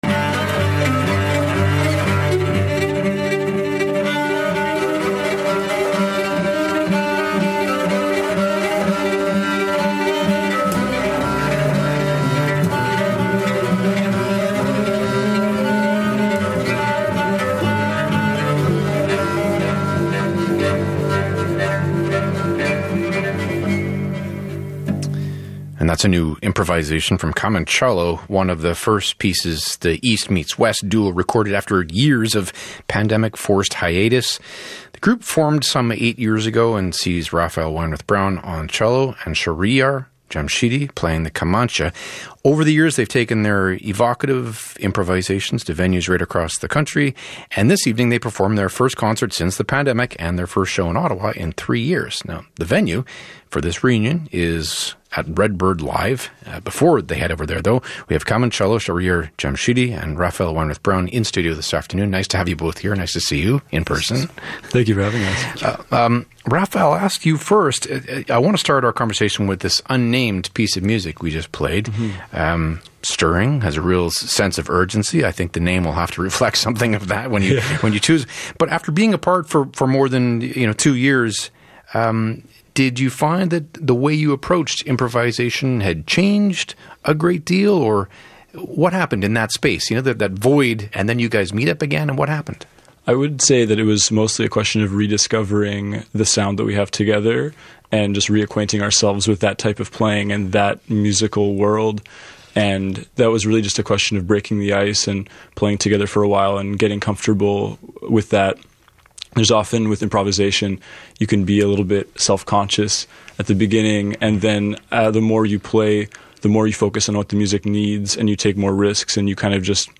Kamancello, Live Interview